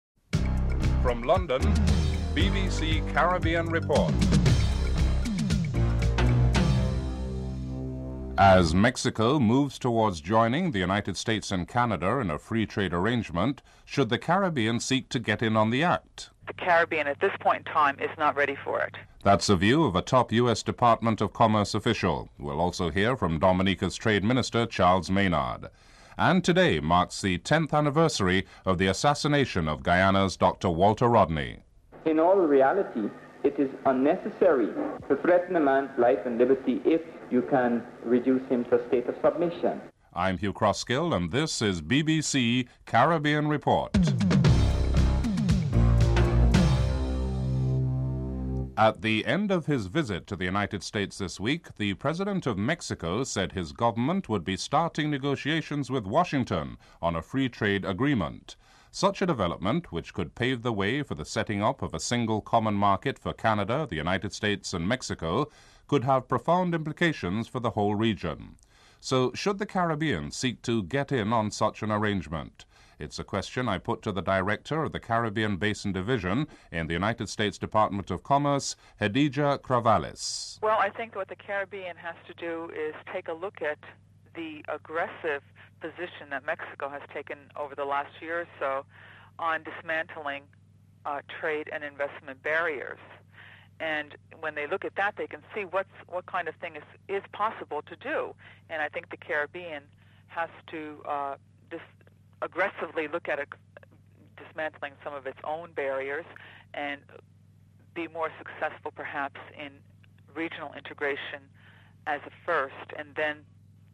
1. Headlines (00:00-00:48)
4. Financial News.